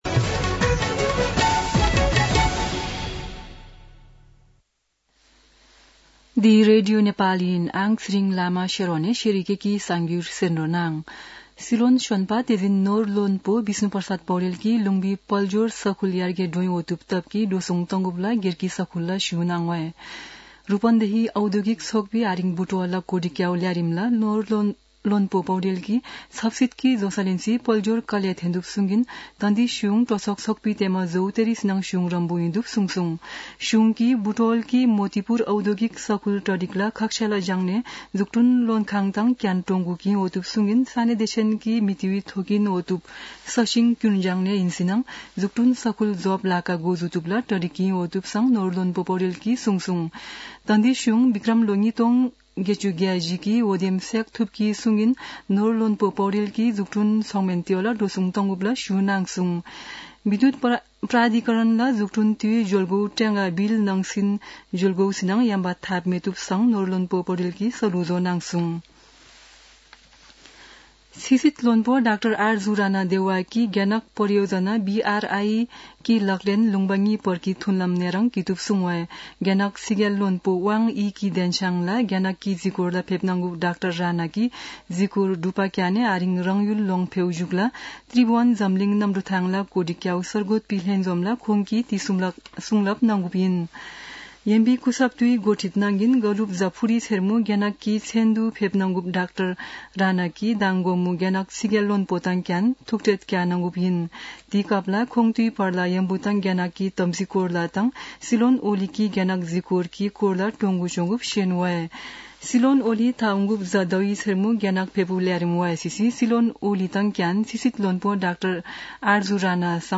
शेर्पा भाषाको समाचार : १६ मंसिर , २०८१
Sherpa-News-15.mp3